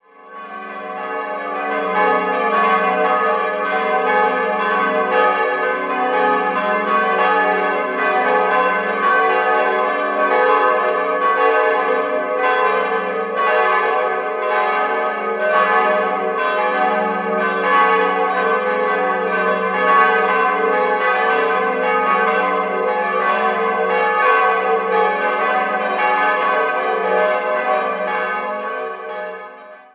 Christusglocke g'-9 822 kg 1.098 mm 2006 Albert Bachert, Karlsruhe Heilig-Geist-Glocke a'-10 575 kg 990 mm 2006 Albert Bachert, Karlsruhe Communioglocke c''-2 357 kg 825 mm 2006 Albert Bachert, Karlsruhe Ökumene-/Friedensglocke d''+0,5 250 kg 734 mm 2006 Albert Bachert, Karlsruhe Quellen: Festschrift zur Glockenweihe/Amt für Kirchenmusik, Eichstätt